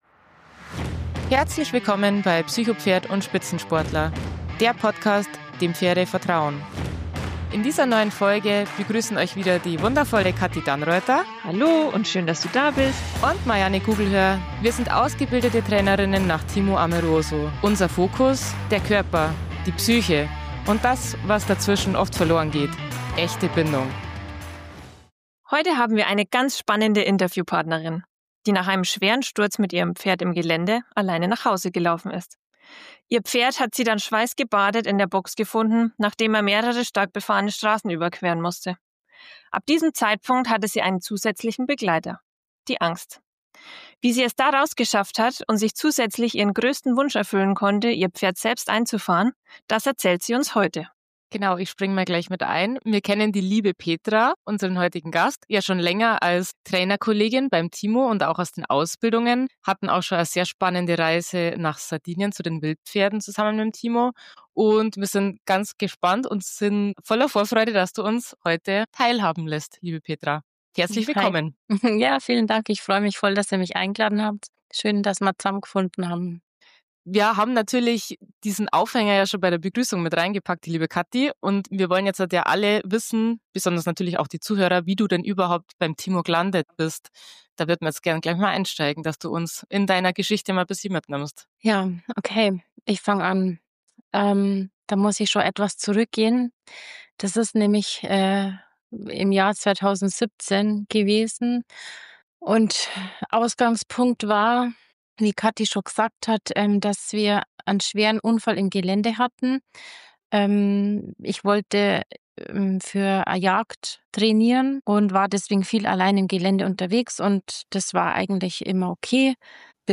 Freut euch auf ein ehrliches, inspirierendes und mutmachendes Interview über Trauma, Vertrauen, innere Arbeit und die unerschütterliche Bindung zwischen Mensch und Pferd.